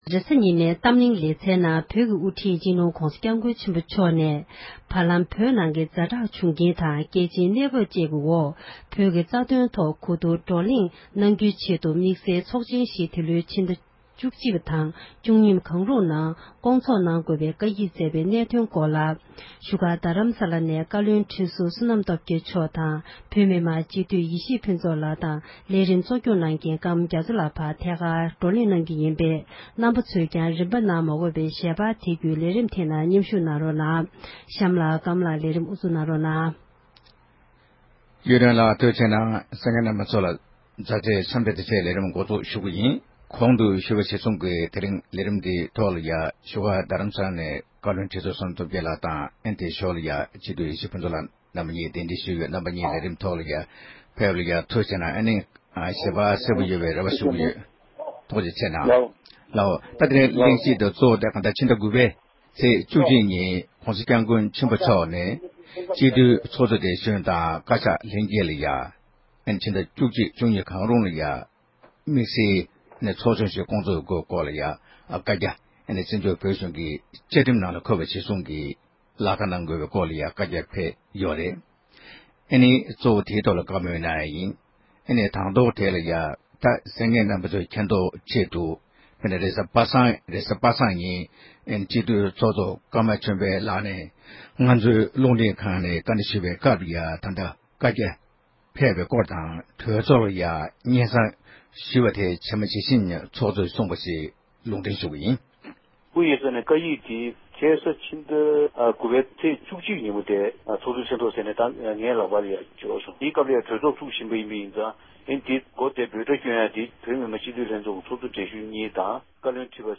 བགྲོ་གླེང་